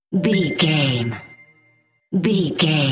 Bright Logo Bell
Sound Effects
Atonal
magical
mystical